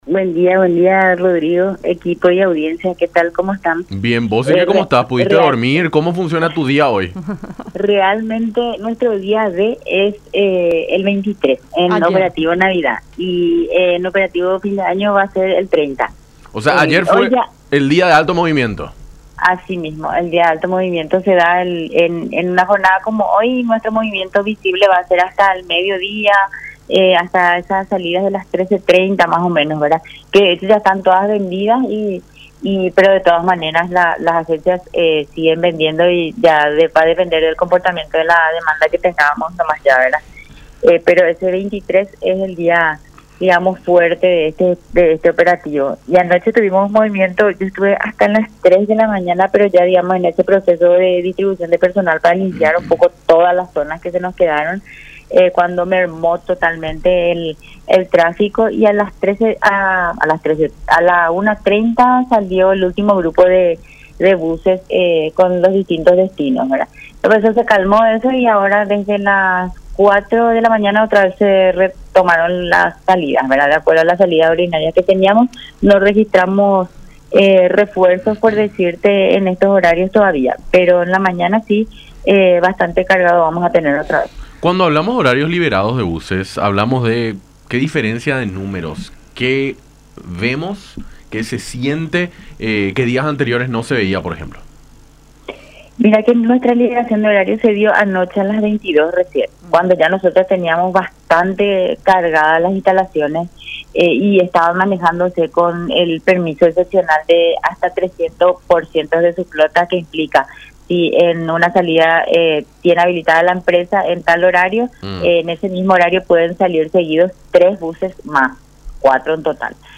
en conversación con Enfoque 800 por La Unión.